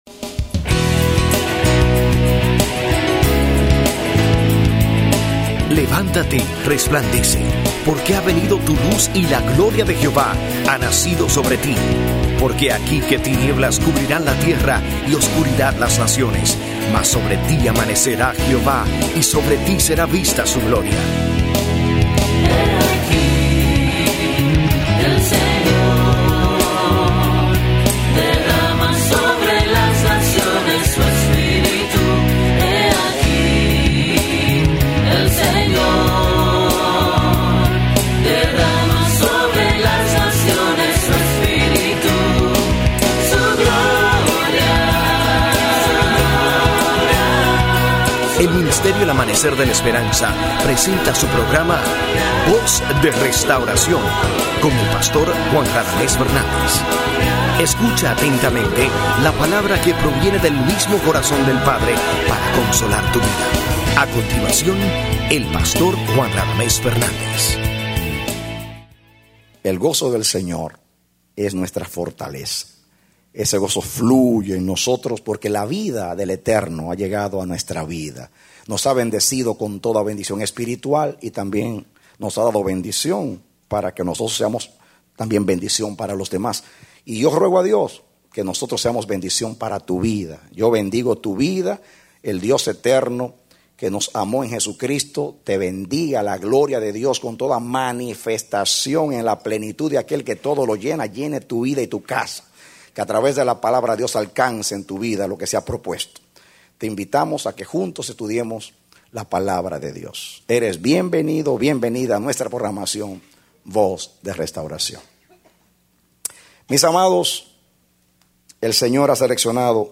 A mensaje from the serie "Mensajes."